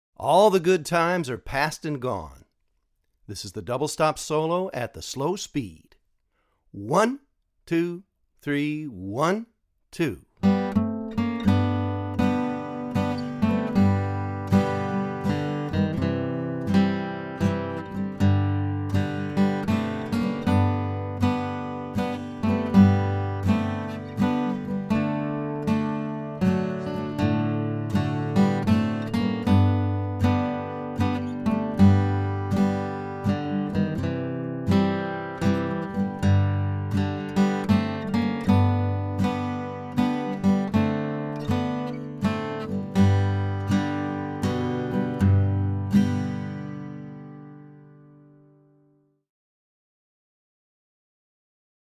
DIGITAL SHEET MUSIC - FLATPICK GUITAR SOLO